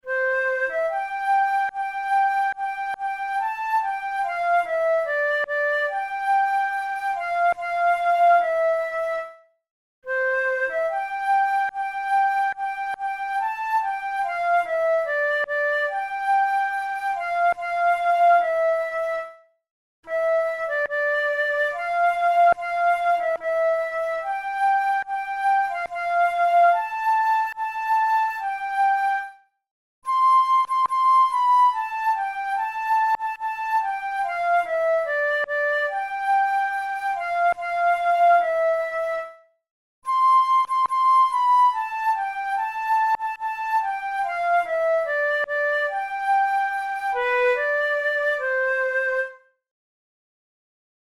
InstrumentationFlute solo
KeyC major
Time signature3/4
Tempo72 BPM
National anthems, Patriotic, Traditional/Folk